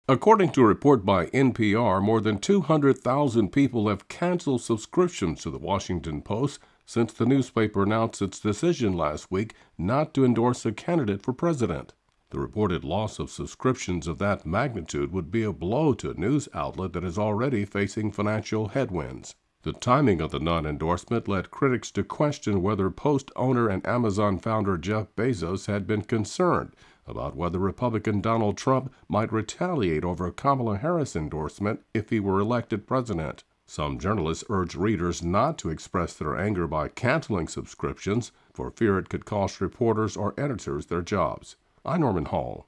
Report: Washington Post loses more than 200,000 subscriptions following non-endorsement